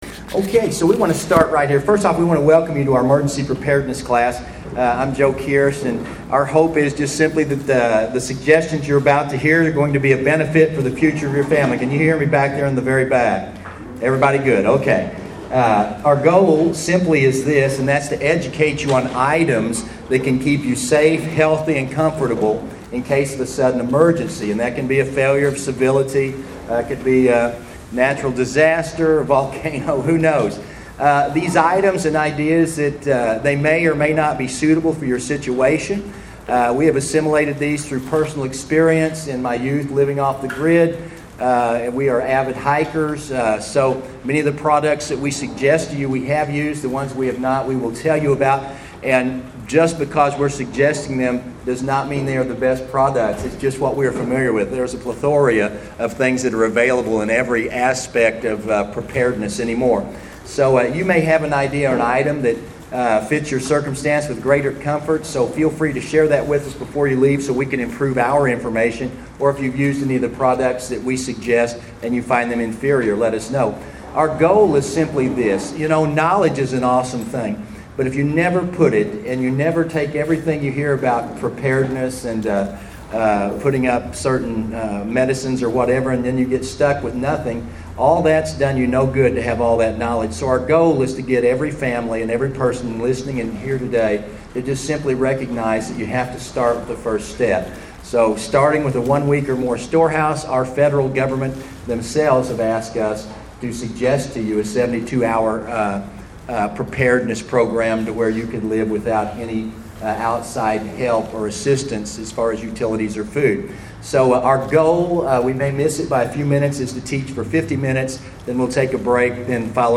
A recording of the “FAMILY PREPAREDNESS” seminar our church conducted. Practical information and advice for all who have not yet assembled a family three day self-sustainment store house.